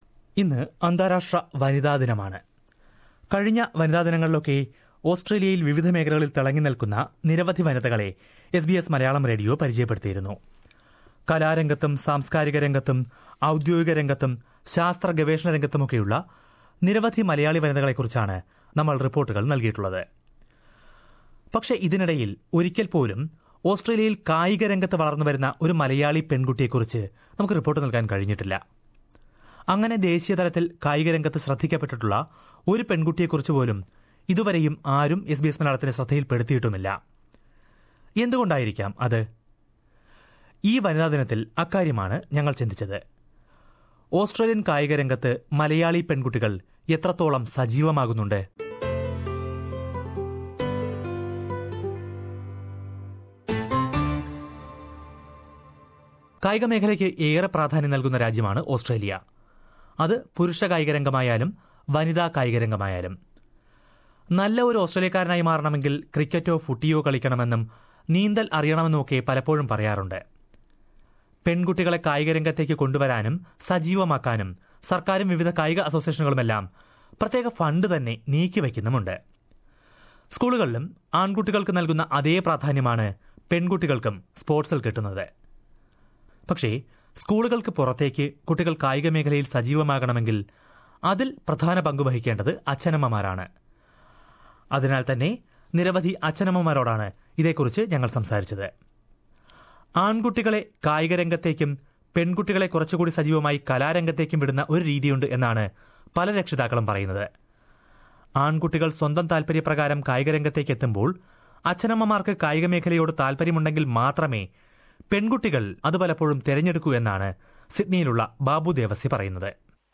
But, how far the young girls from the Malayalee community make use of those opportunities? On the International Women's Day, SBS Malayalam talked to various parents to find this out...